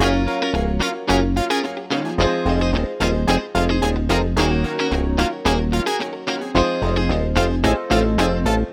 03 Backing PT4.wav